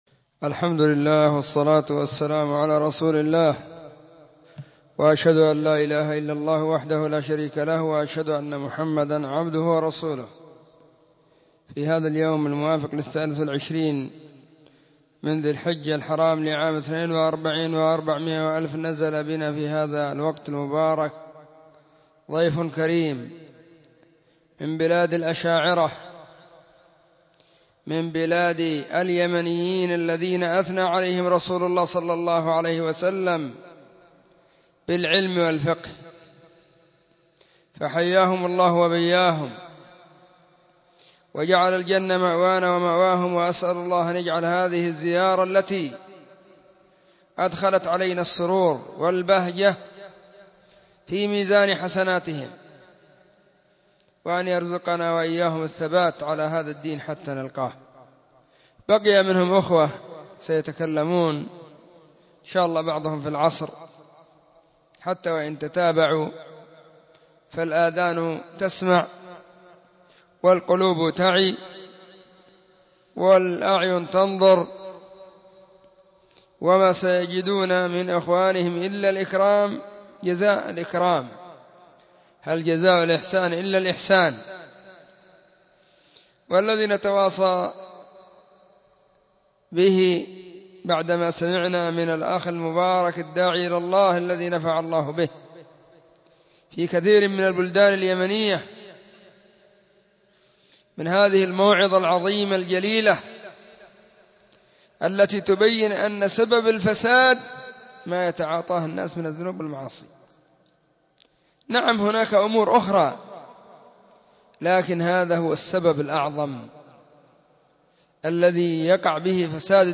🎙كلمة بعنوان:الترحيب بالضيوف من بلاد الاشاعرة